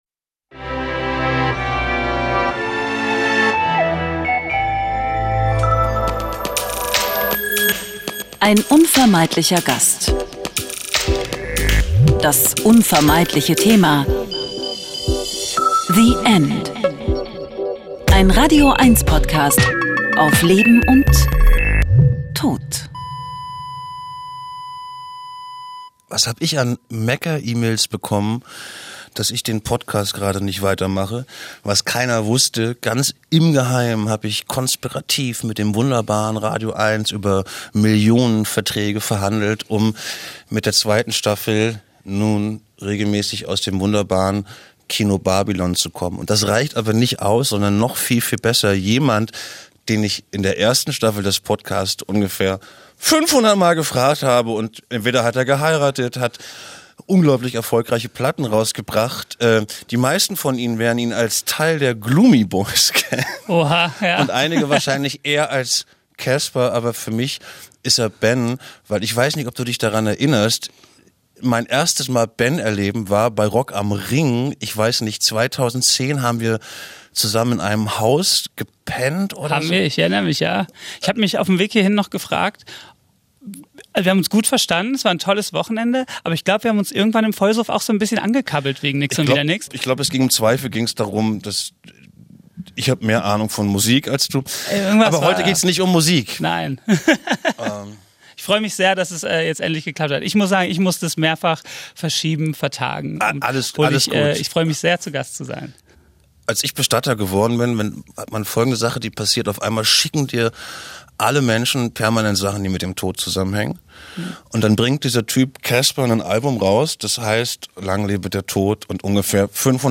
„Lang lebe der Tod“, nennt Casper sein letztes Album. Ein Gespräch mit einem der erfolgreichsten Rapper Deutschlands über Abschied und Angst, Regeln vor der Himmelstür, Songs für Trauerfeiern und über die Frage was Tod und Fußball gemeinsam haben.